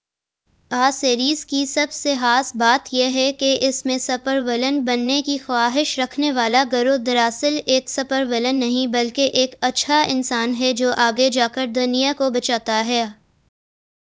deepfake_detection_dataset_urdu / Spoofed_TTS /Speaker_01 /103.wav